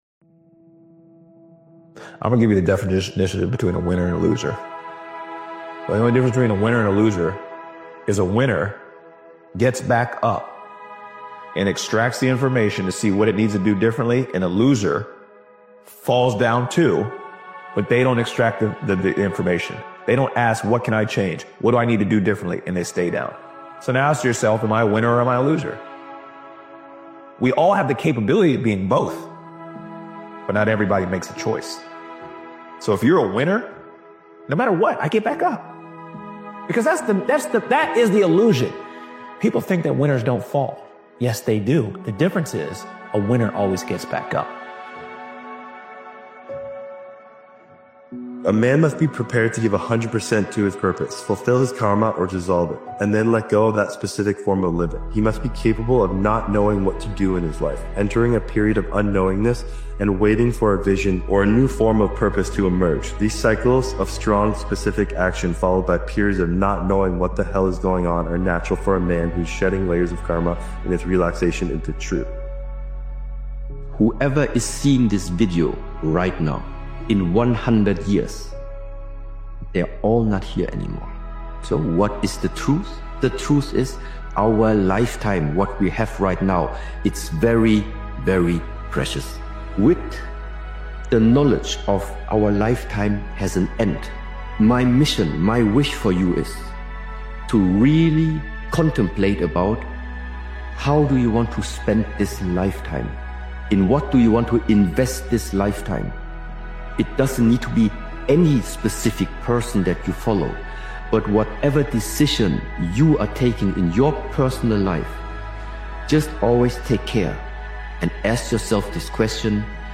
This powerful motivational speeches compilation is a reminder to stop pouring energy into distractions, opinions, and things you can’t control. Real change starts when you turn inward, take responsibility, and commit to your own growth.